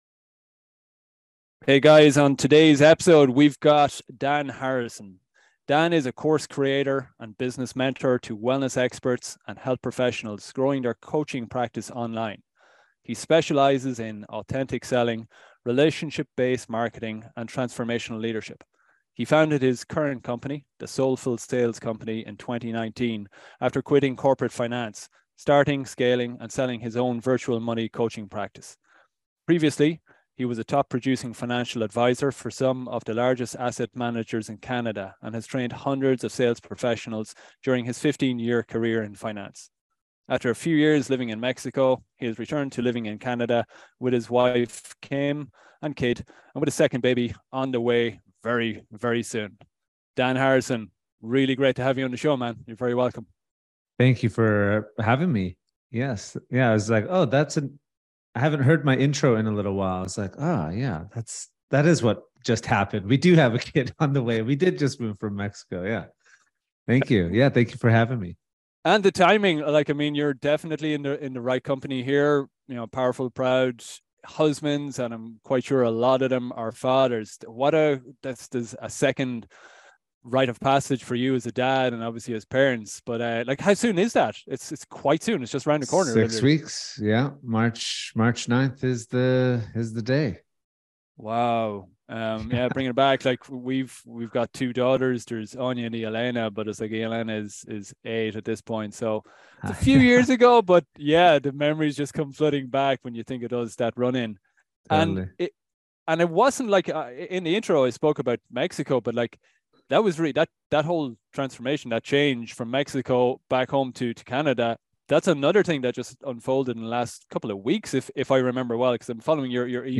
Enjoy a beautiful conversation